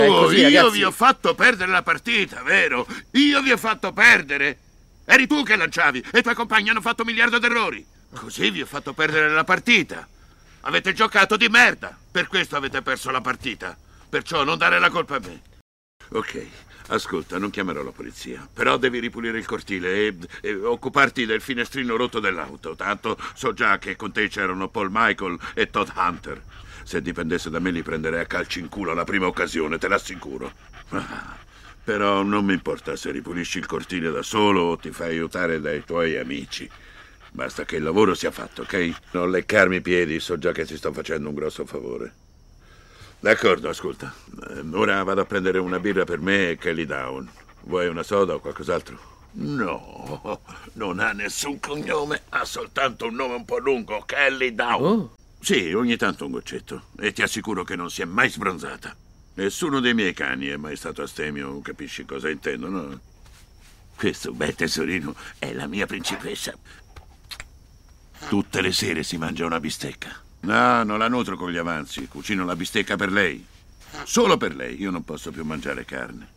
Il mondo dei doppiatori